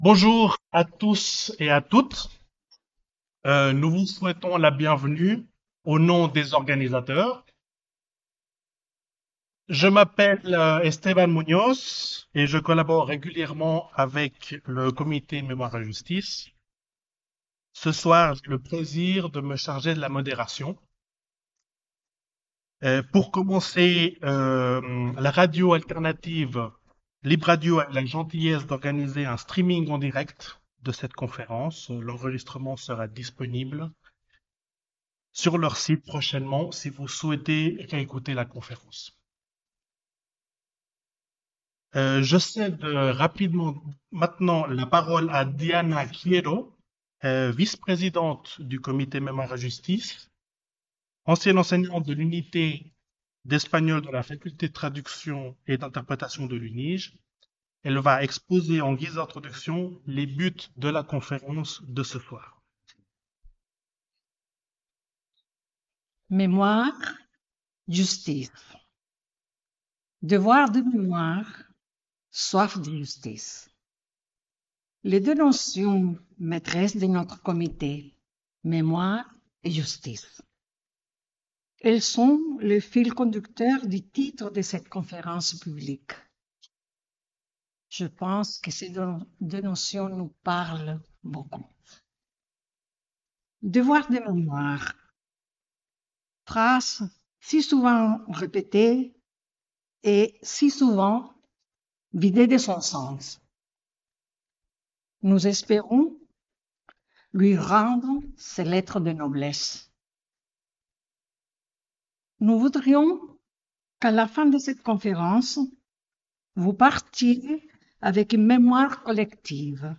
Le 22 mai 2025 une conférence publique se tenait à l’Université de Genève pour évoquer les aspects juridiques des disparitions forcées ou involontaires et se pencher avec émotion et respect sur la tragique histoire de la colonie Dignitad.
Questions et réponses